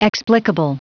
Prononciation du mot explicable en anglais (fichier audio)